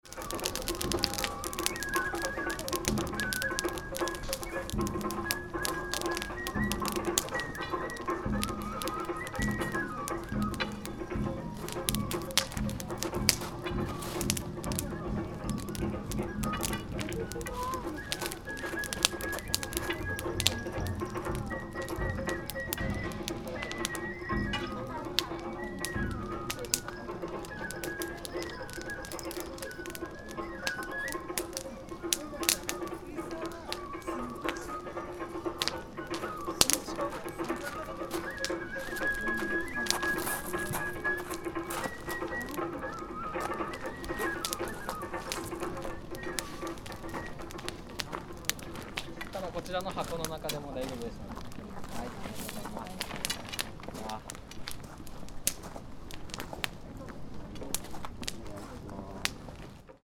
Gokoku Shrine
Don-do Yaki festival was held at Gokoku Shrine as usual year.
Like last year, many worshippers stayed around the fire only briefly, although worshippers visited the shrine ceaselessly.
Recorded Kagura music was played through PA Speakers, similar to usual year.